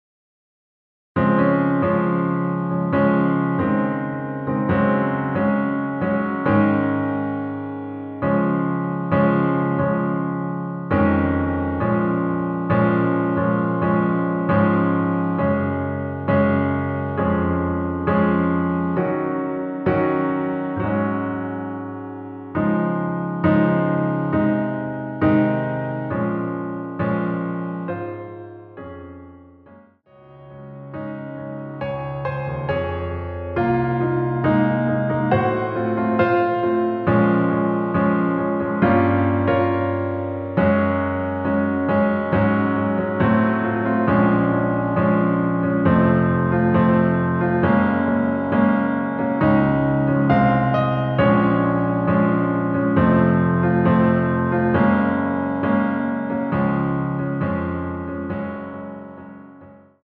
반주를 피아노 하나로 편곡하여 제작하였습니다.
원키(Piano Ver.) (1절+후렴) MR입니다.